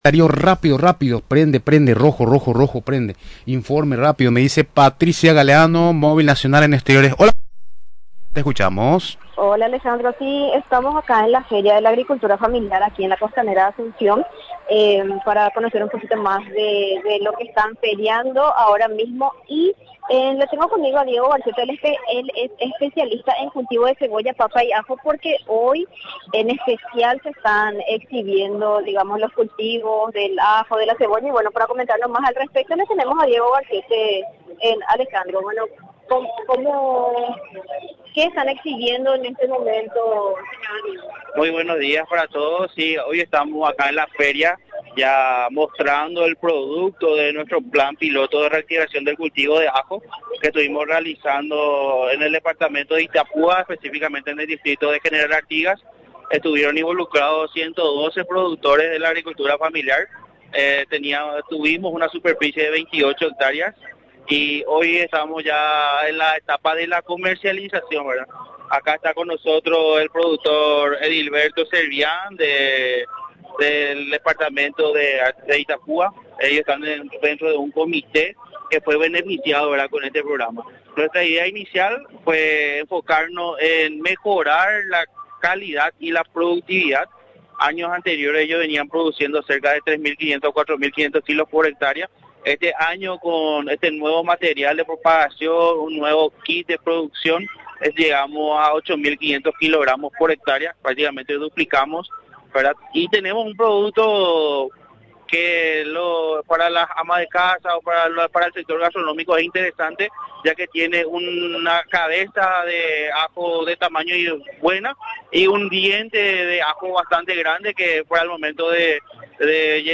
En el momento de la entrevista, ya se encontraban en la etapa de comercialización de la cosecha.